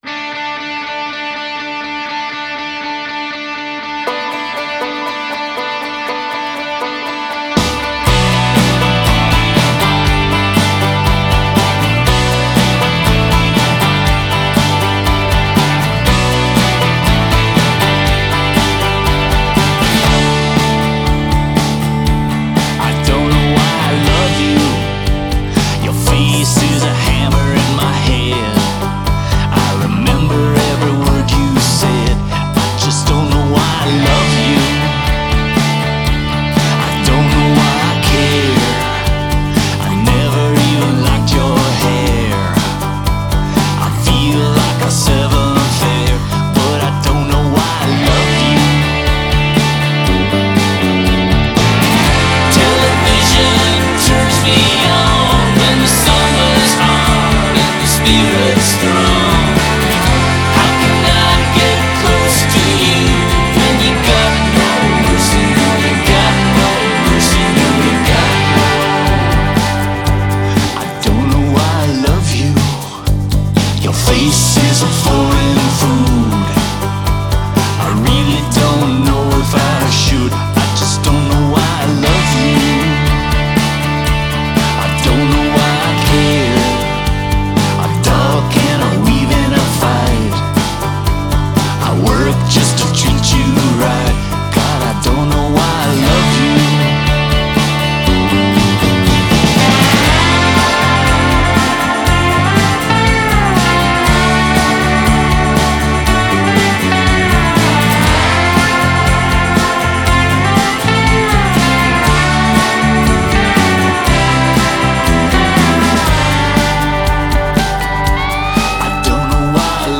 for its humour and super banjo